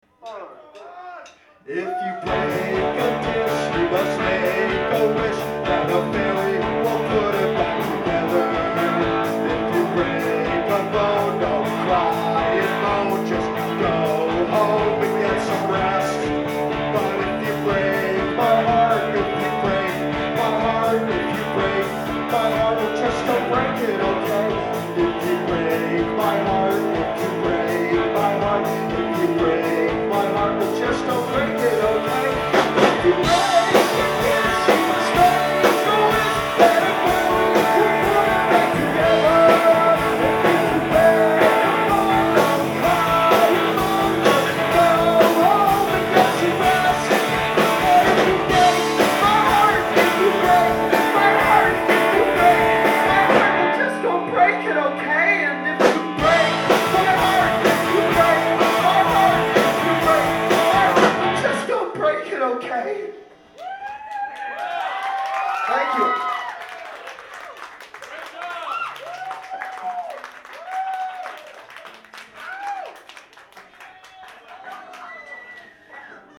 Live at the Middle East Upstairs
in Cambridge, MA